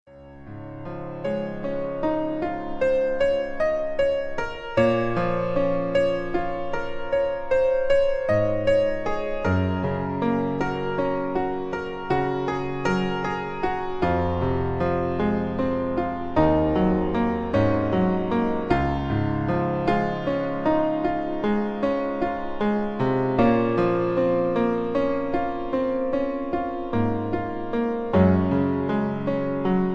pop music, top 40, easy listening